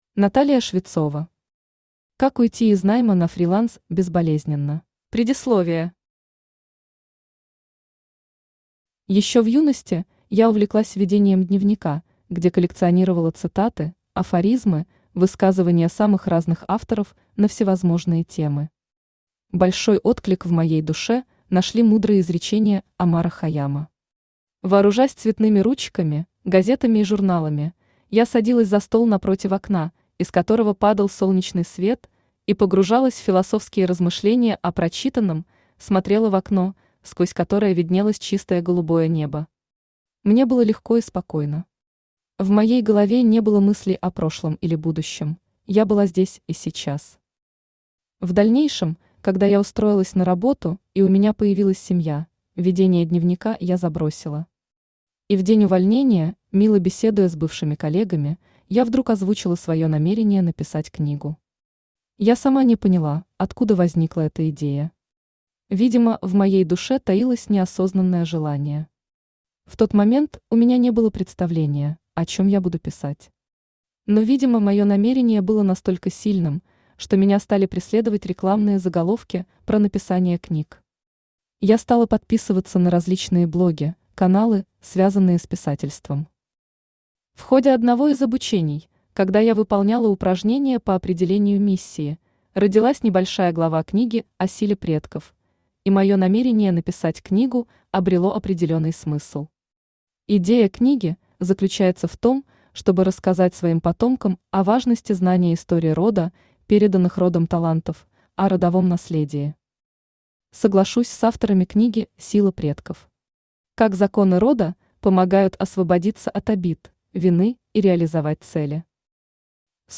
Аудиокнига Как уйти из найма на фриланс безболезненно | Библиотека аудиокниг
Aудиокнига Как уйти из найма на фриланс безболезненно Автор Наталия Швецова Читает аудиокнигу Авточтец ЛитРес.